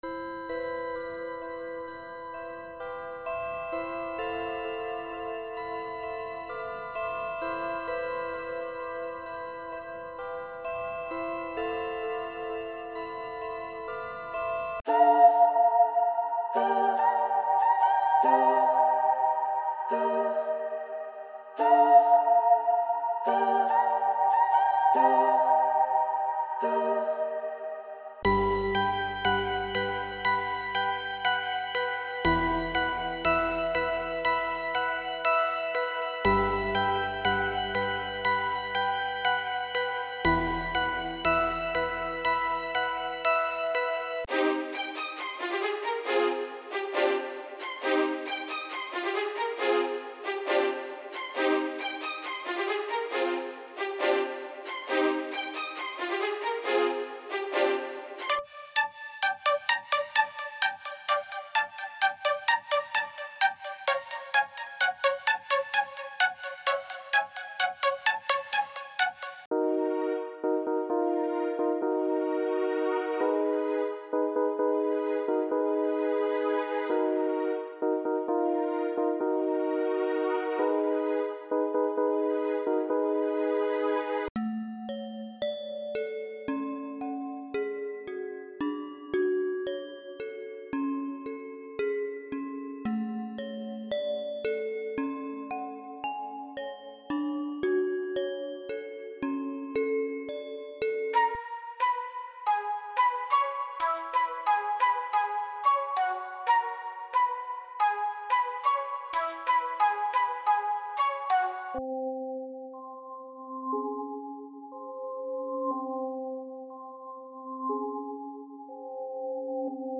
• 16 Melody Loops